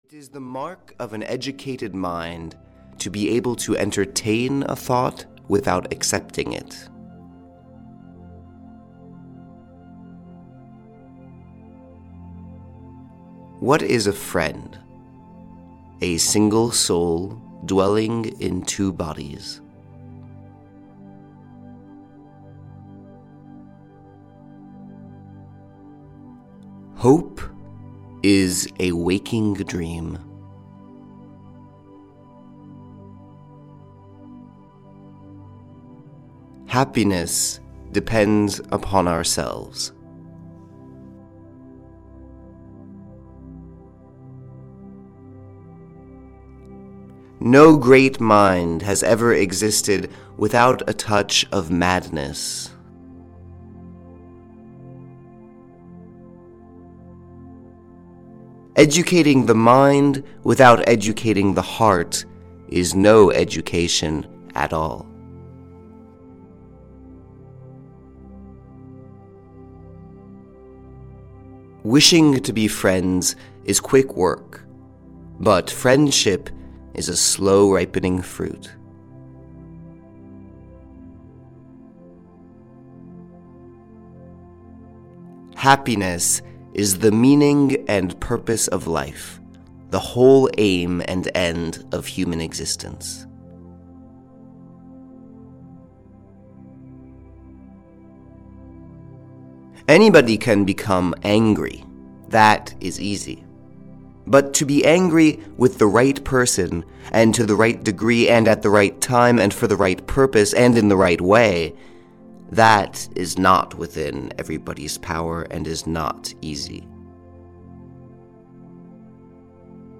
600 Quotes of Ancient Philosophy (EN) audiokniha
Ukázka z knihy